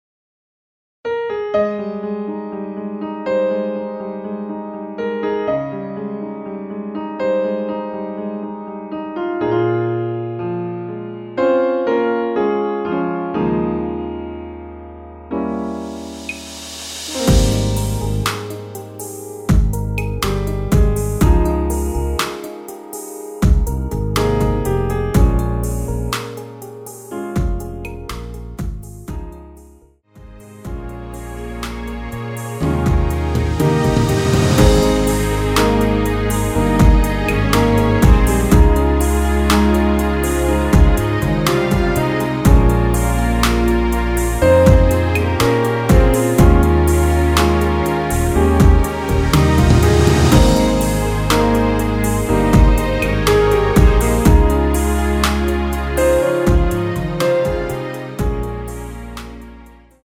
MR 입니다.
원곡의 보컬 목소리를 MR에 약하게 넣어서 제작한 MR이며